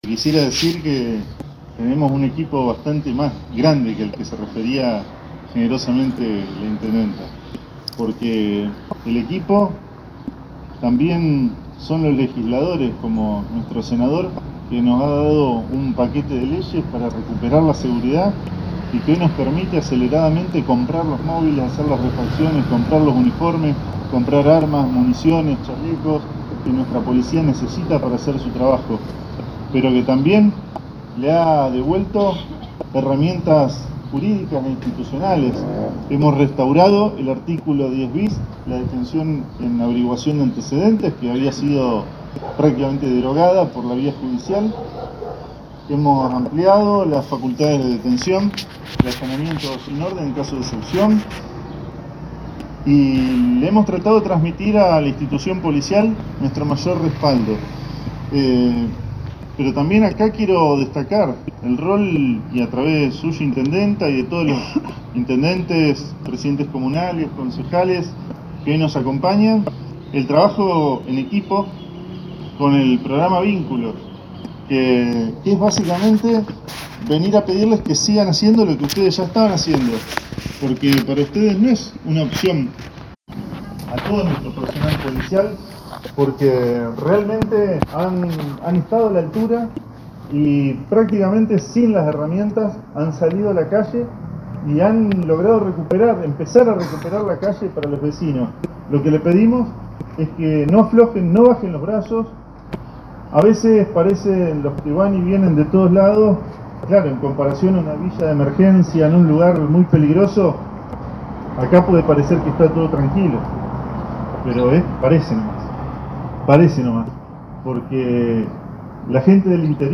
En un acto  para la seguridad del departamento San Martín, el gobierno provincial concretó la entrega de cuatro camionetas Fiat Toro totalmente equipadas a la Unidad Regional XVIII.